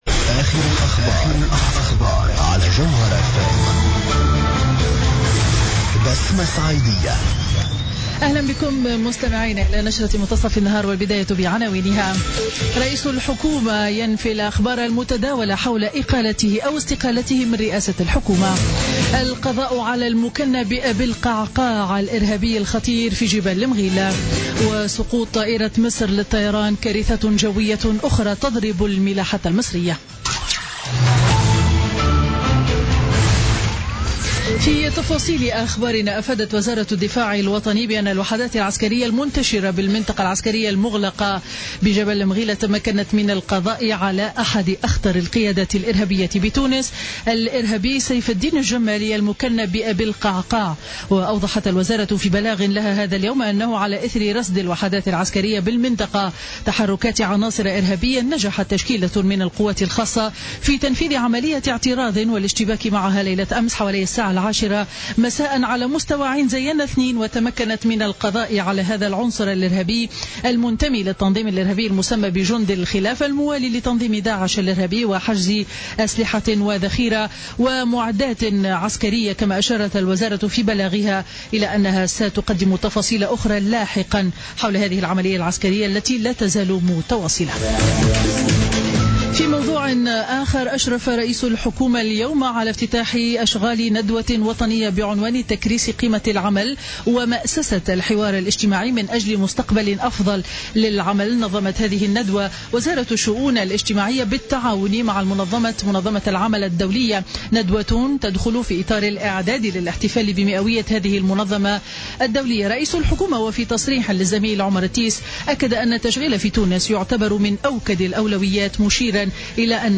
نشرة أخبار منتصف النهار ليوم الخميس 19 ماي 2016